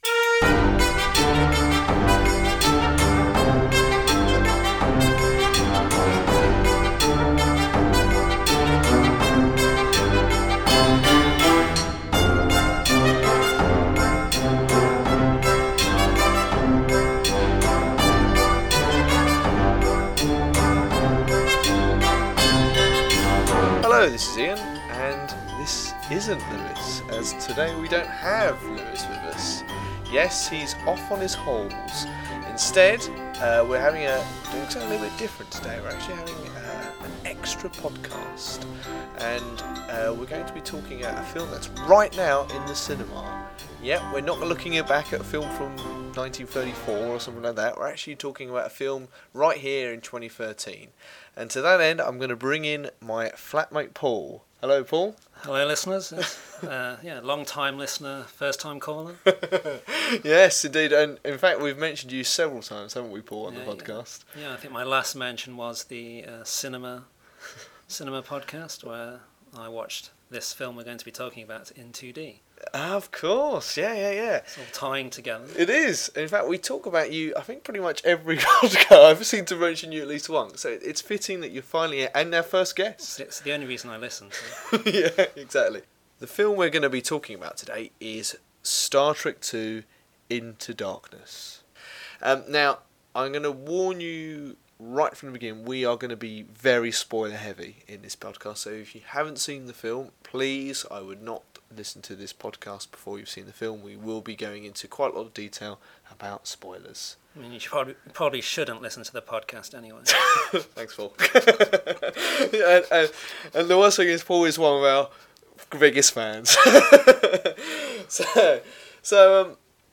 The Extras will be stripped down; shorter, no Trivia Quiz and no Secret Sponsor.